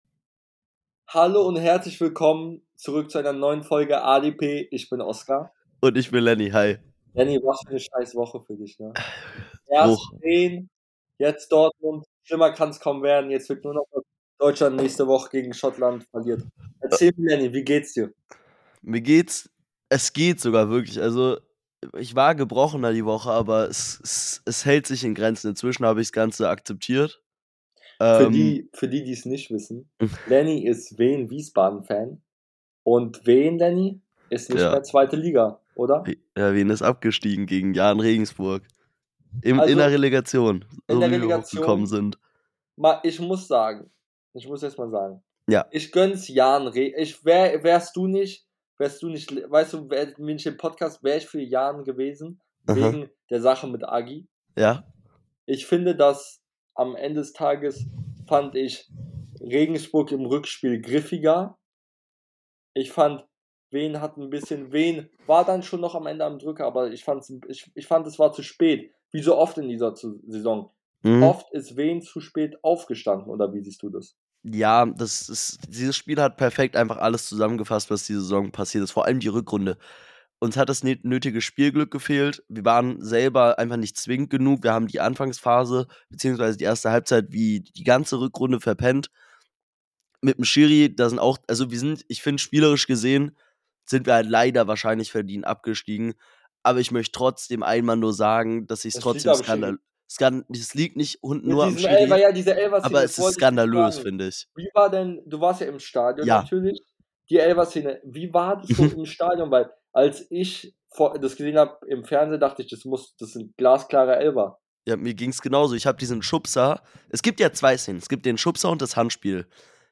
In der heutigen Folge reden die beiden Hosts über Abstieg, Dortmunds Finalniederlage, Fenerbahces neuen Trainer und vieles mehr.